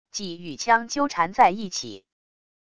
戟与枪纠缠在一起wav音频